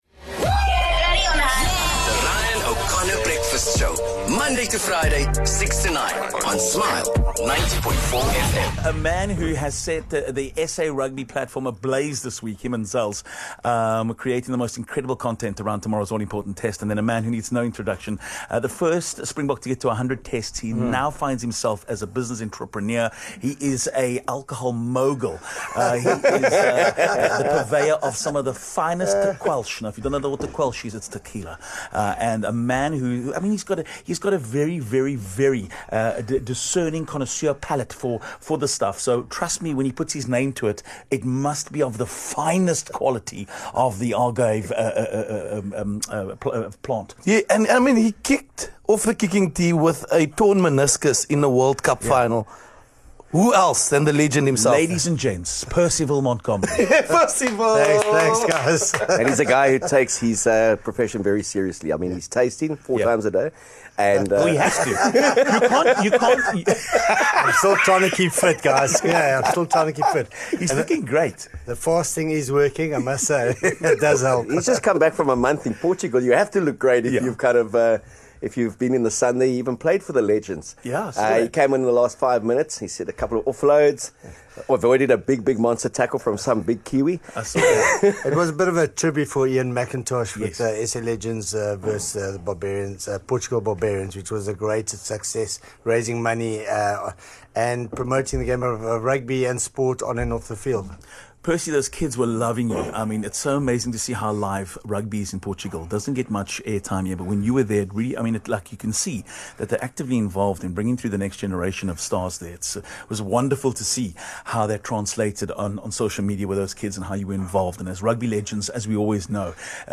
He brought a special guest in Springbok legend Percy Montgomery.